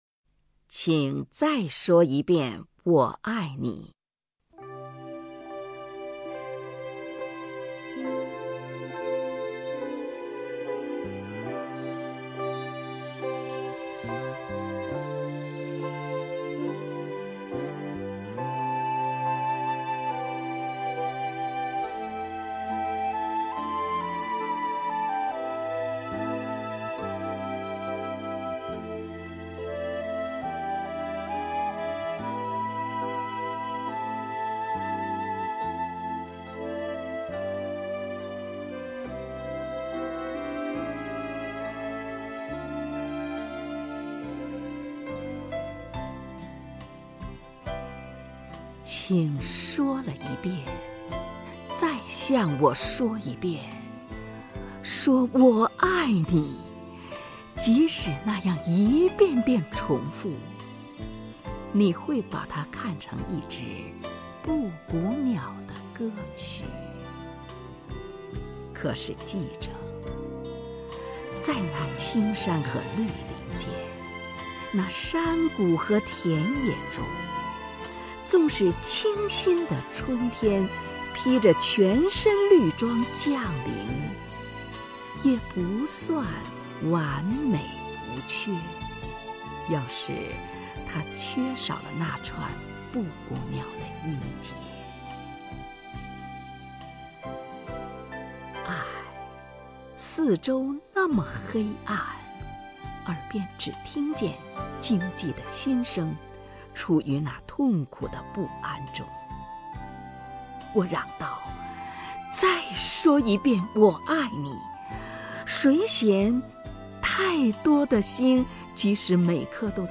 首页 视听 名家朗诵欣赏 虹云
虹云朗诵：《请再说一遍“我爱你”》(（英）伊丽莎白·芭蕾特·布朗宁)